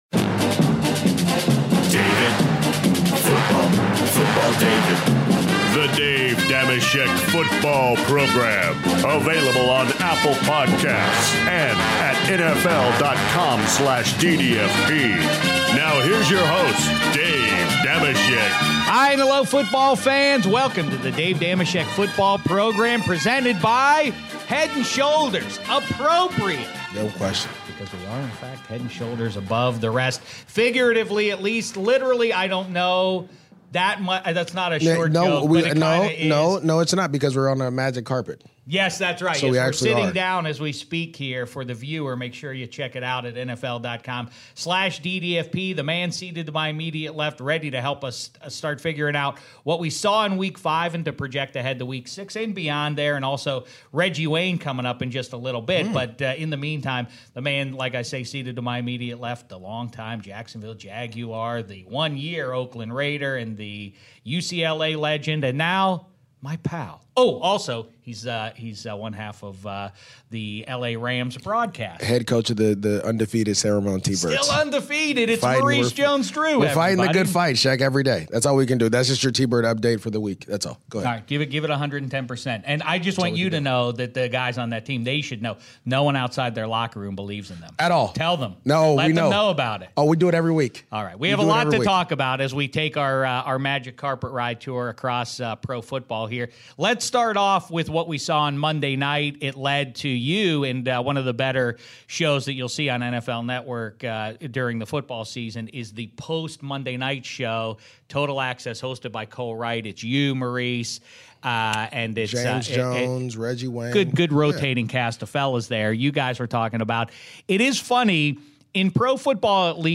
Dave Dameshek leaves Studio 66 to sit on the magic carpet in the green room with Maurice Jones-Drew. Shek and MJD start kibitzing about Drew Brees' record breaking performance during Monday Night Football while also debating where he ranks all-time (2:05)?
Next, Reggie Wayne joins Shek for his Week 5 exit interview to discuss why Peyton Manning is always seen in Broncos gear instead of Colts (21:55) and who he'd pick as his QB to win one game, Peyton or Brees (25:50)?